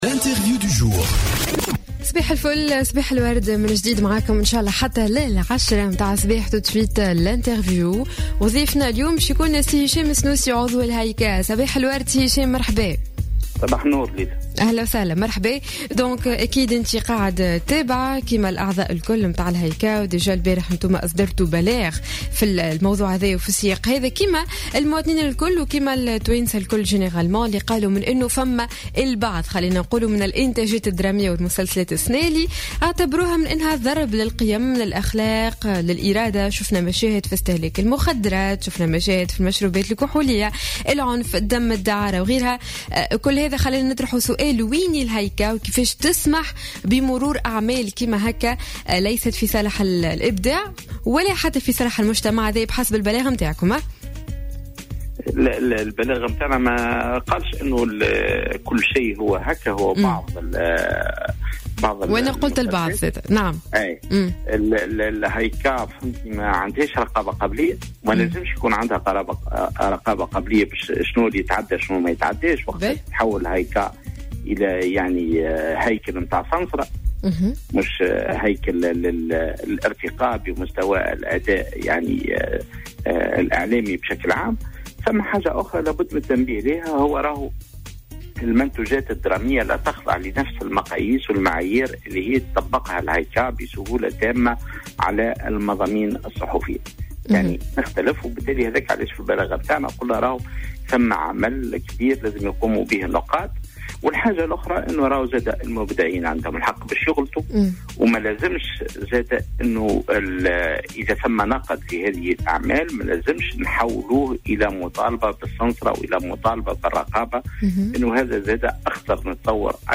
أكد عضو الهيئة العليا المستقلة للإتصال السمعي البصري هشام السنوسي في مداخلة له على جوهرة "اف ام" صباح اليوم الخميس 9 جويلية 2015 أن الهايكا لا يمكن ان يكون لديها رقابة قبلية للإنتاجات التي تعرض معتبرا أنها لايمكن أن تصبح هيكل صنصرة بدل ان تكون هيكلا للارتقاء بالأداء الاعلامي